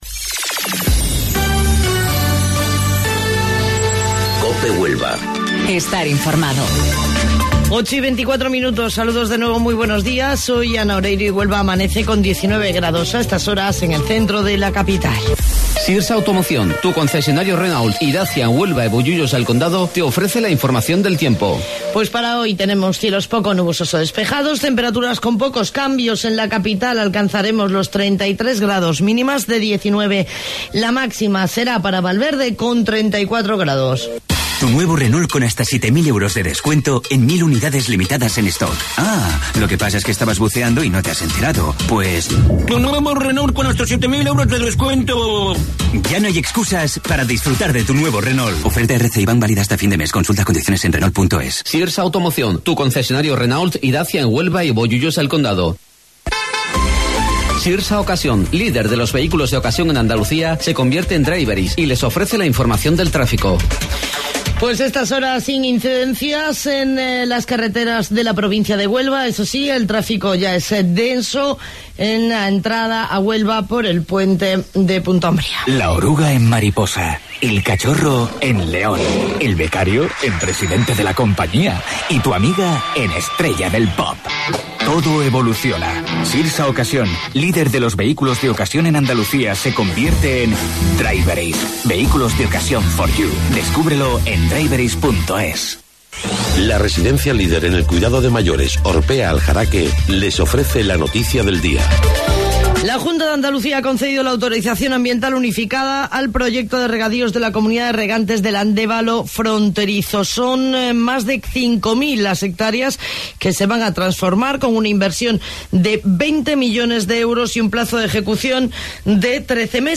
AUDIO: Informativo Local 08:25 del 1 de Agosto